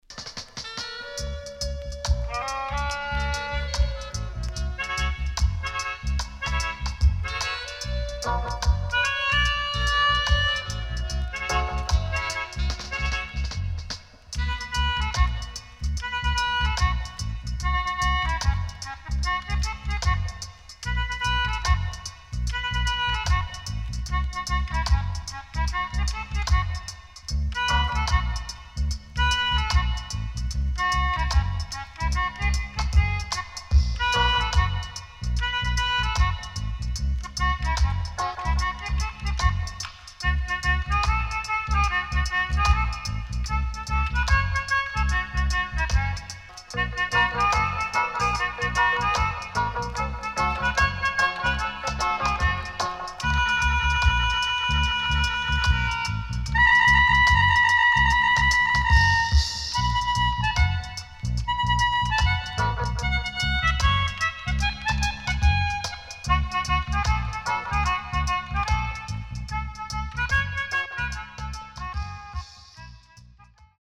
riddim
SIDE A:少しチリノイズ入りますが良好です。